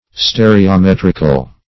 Search Result for " stereometrical" : The Collaborative International Dictionary of English v.0.48: Stereometric \Ste`re*o*met"ric\, Stereometrical \Ste`re*o*met"ric*al\, a. [Cf. F. st['e]r['e]om['e]trique.]